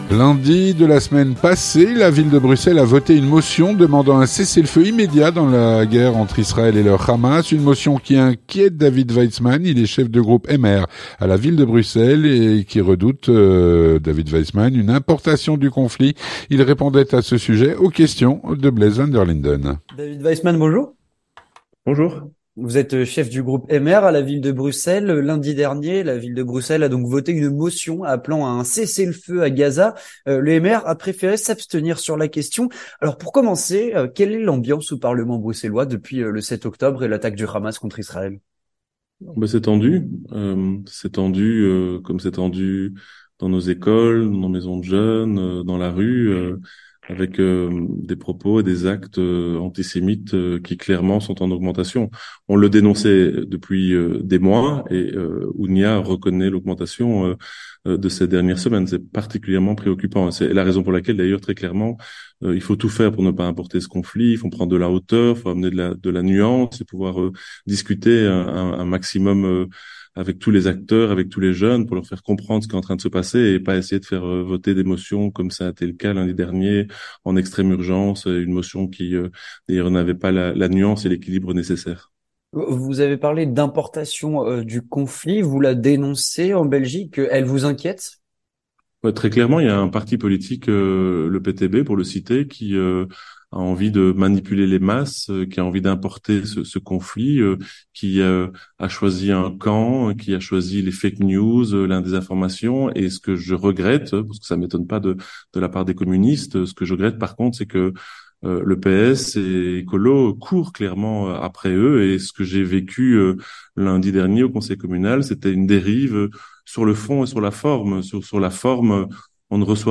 Avec David Weytsman, chef de groupe MR à la Ville de Bruxelles, qui redoute une importation du conflit.